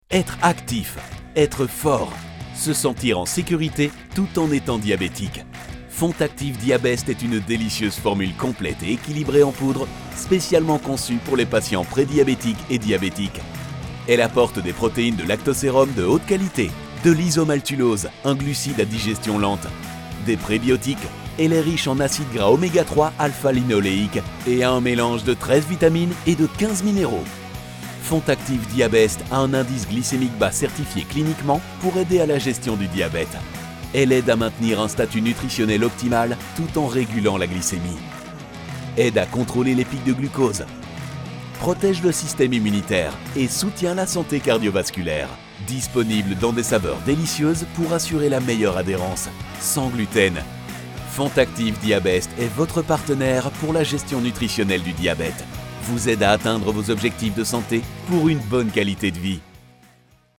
French Male Voice Over Artist
Confident, Corporate, Natural, Reassuring, Warm
Audio equipment: StudioBricks booth, RME Babyface interface, CAD EQuitek E100S mic